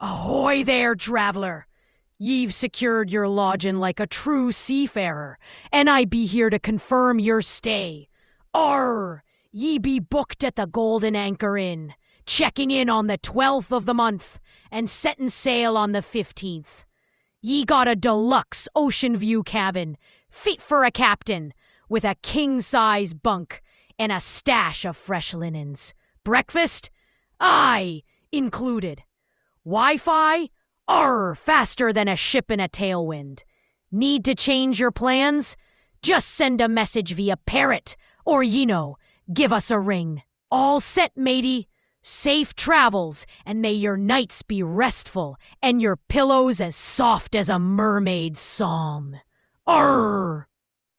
You can now instruct these models to speak in specific ways.
openai-fm-coral-pirate.wav